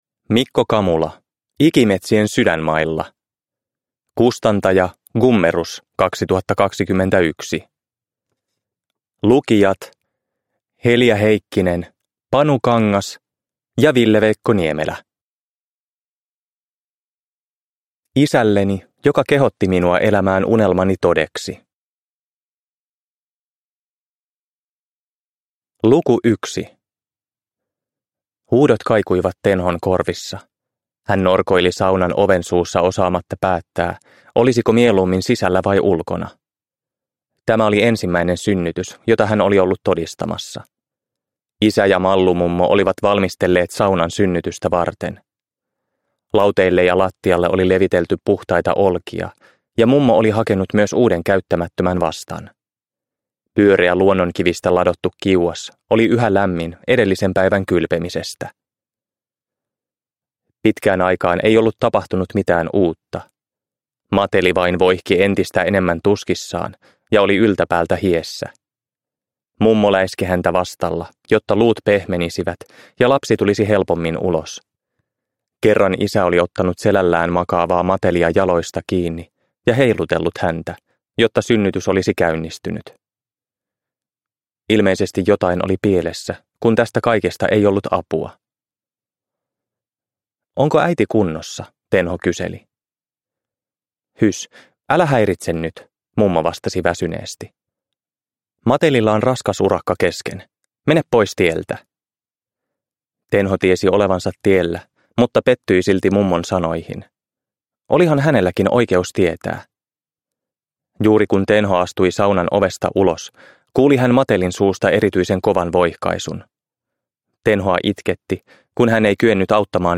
Ikimetsien sydänmailla – Ljudbok – Laddas ner